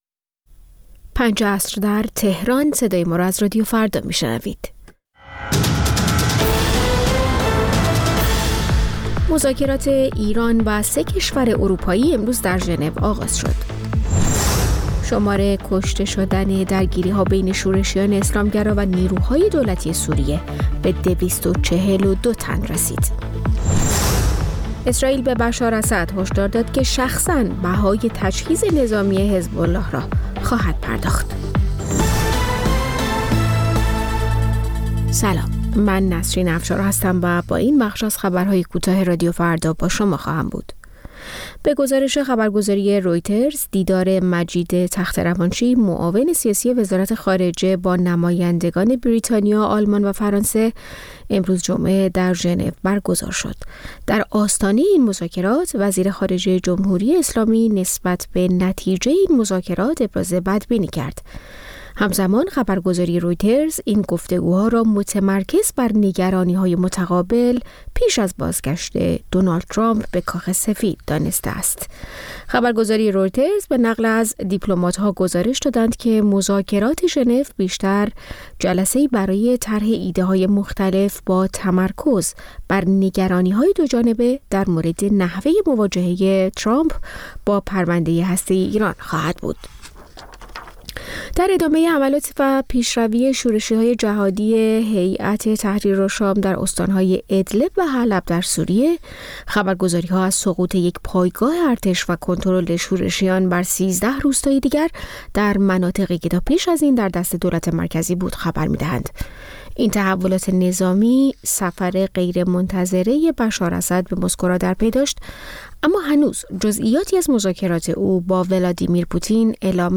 سرخط خبرها ۱۷:۰۰